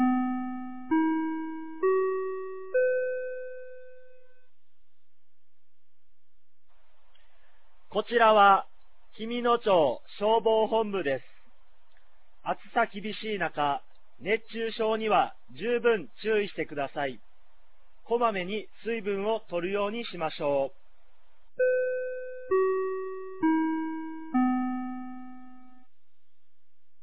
2025年07月12日 16時00分に、紀美野町より全地区へ放送がありました。